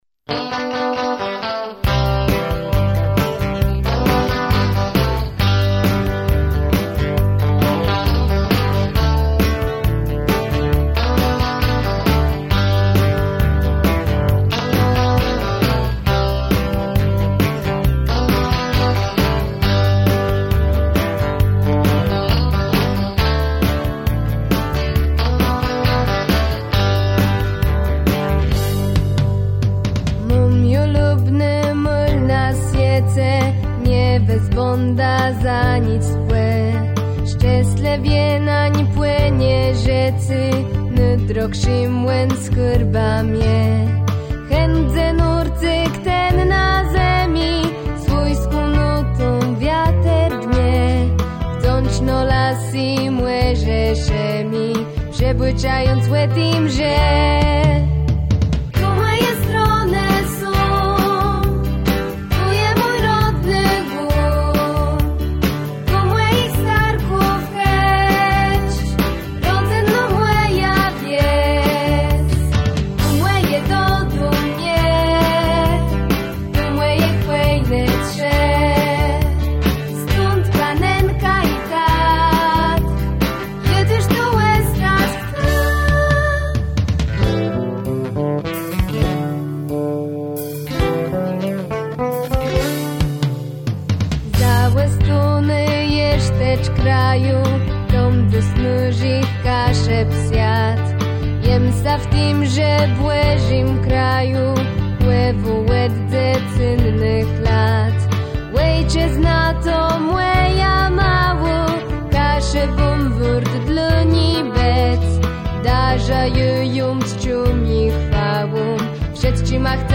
W ramach wolnego czasu tworzymy własne utwory muzyczne w języku kaszubskim, do rytmów muzyki współczesnej.